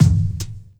Medicated Kick 6.wav